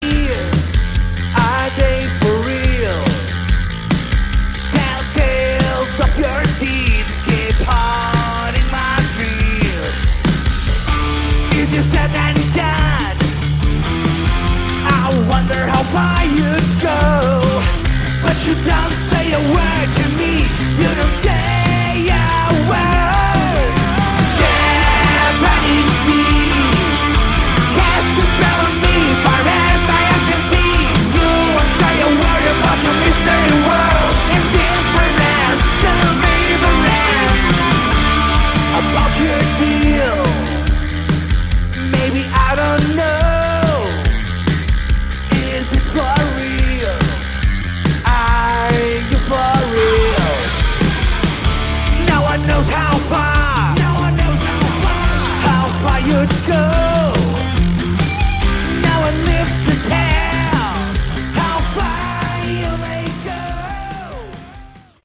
Category: Hard Rock
guitar, backing vocals
bass, backing vocals
drums, backing vocals